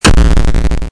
Retro Game Weapons Sound Effects – 8-bit-fireball – Free Music Download For Creators
Retro_Game_Weapons_Sound_Effects_-_8-bit-fireball.mp3